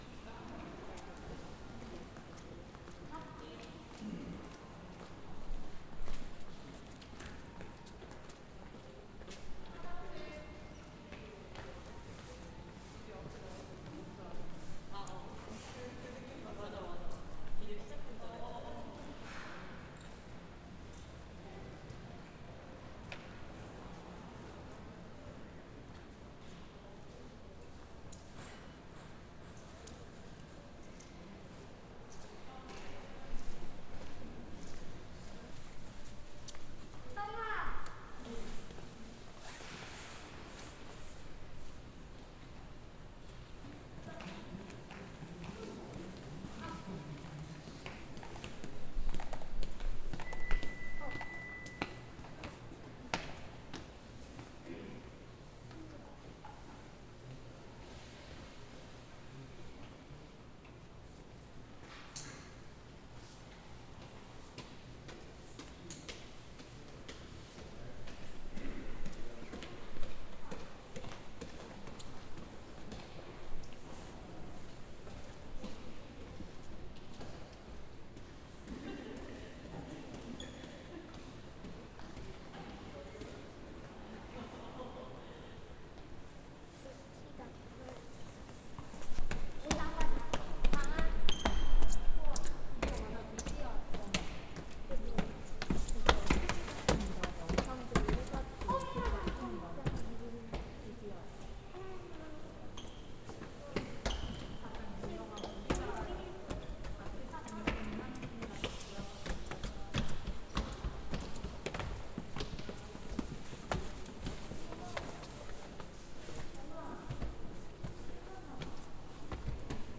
로비+계단.mp3